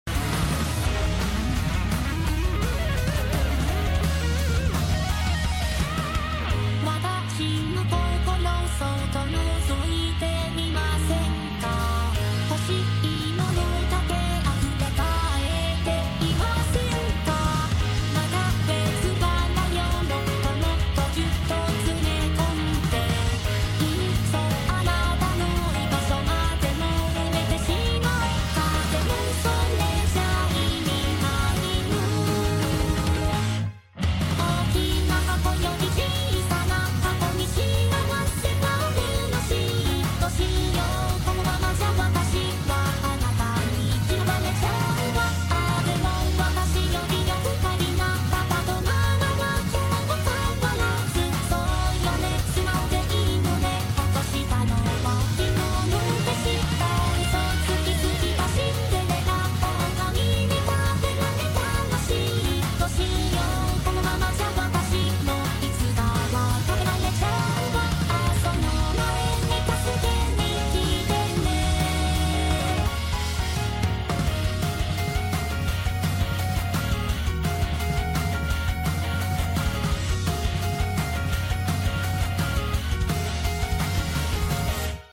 Audio pitched down